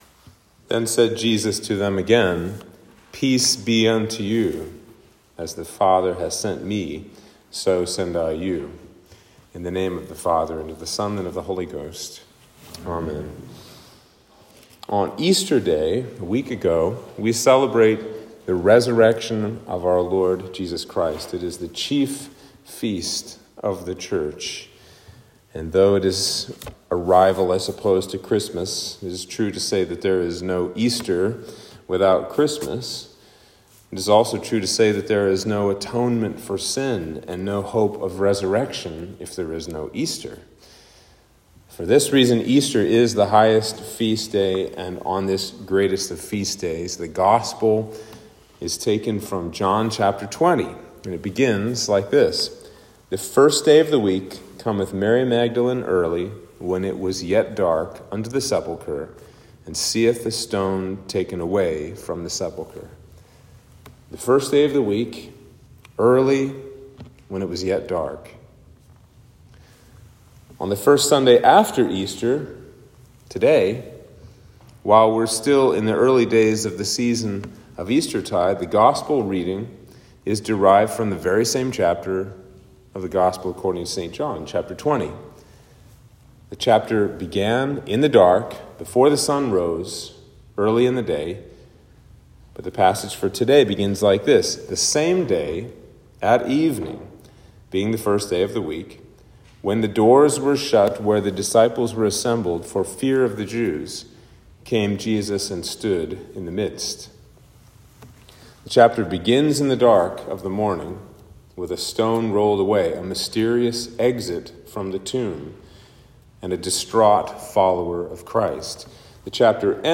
Sermon for Easter 1